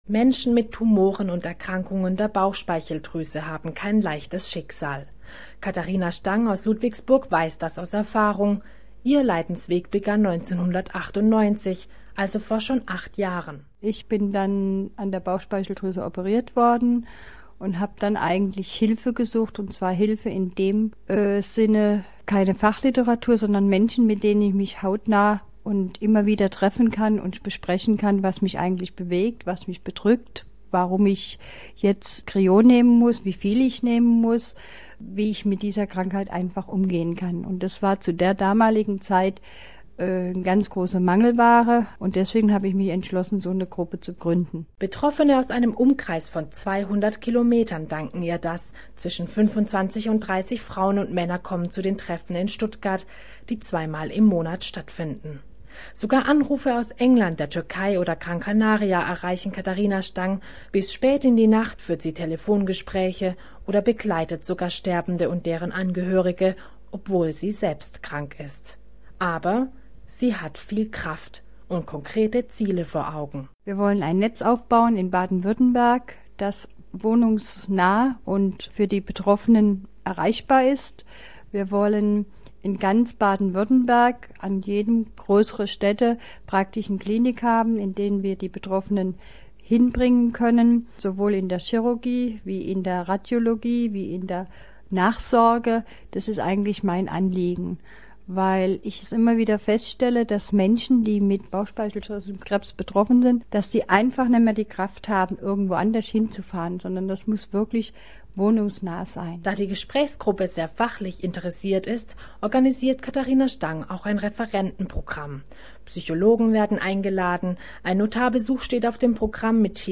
Reportage zum Zuhören